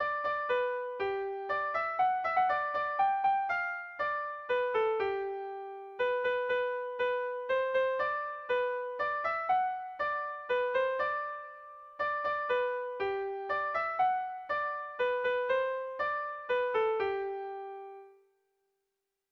Erromantzea
ABDAB